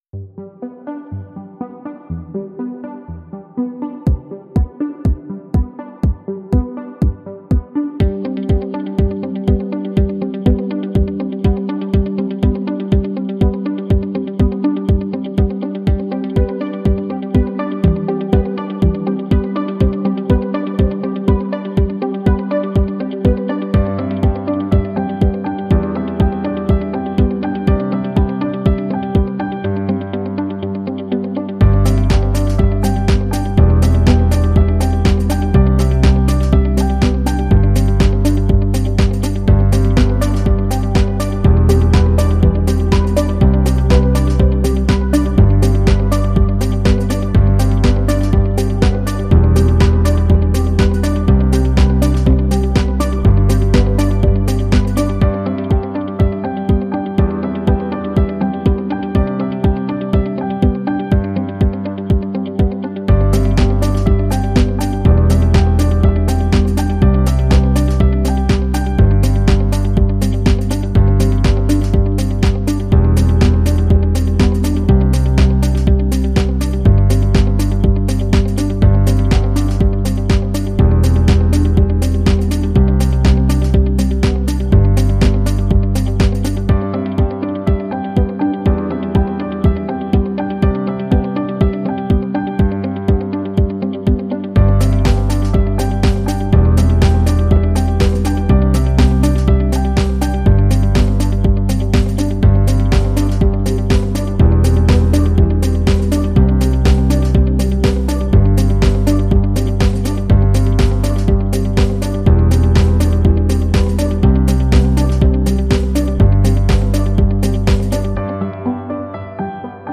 Simple and light music track